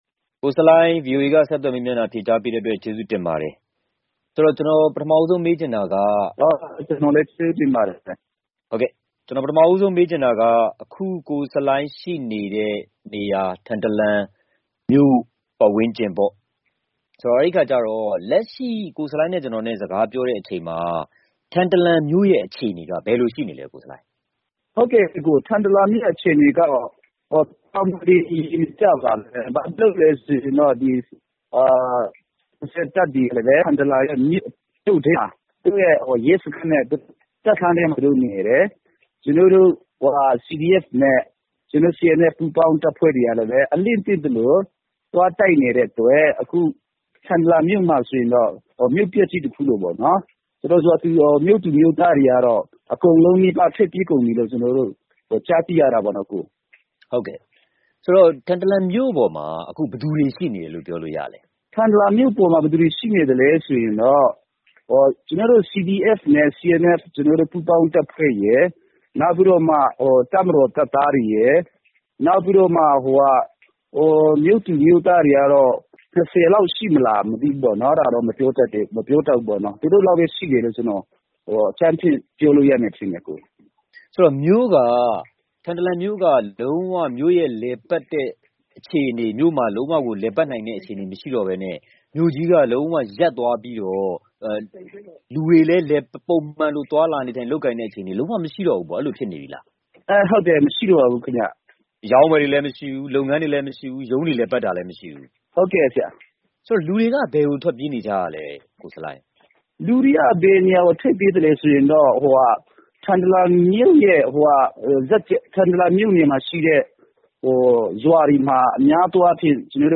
ချင်းပြည်နယ်၊ ထန်တလန်မြို့ရဲ့ လက်ရှိ နောက်ဆုံးအခြေအနေအေကြောင်း ဆက်သွယ် မေးမြန်းထားပါတယ်။